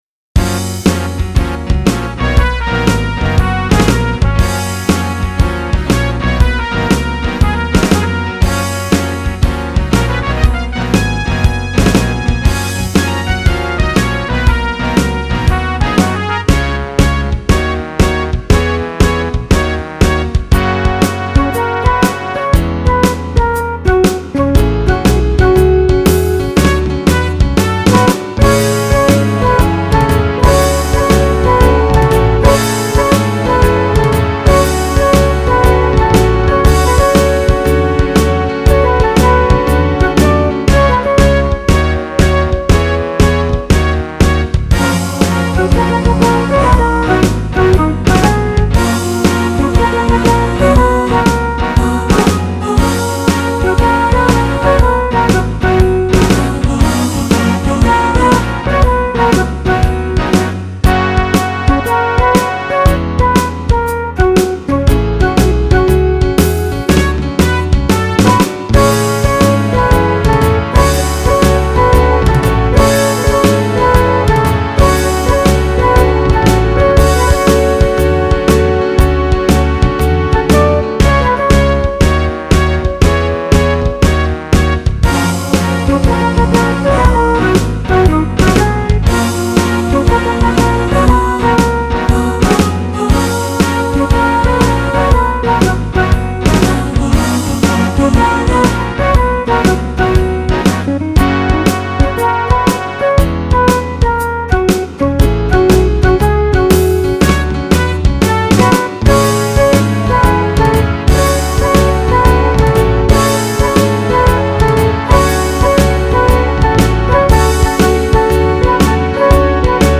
Combine big band with rock’n’roll and what do you get?
Take note of my “fake” backup vocals.  Love this keyboard!!!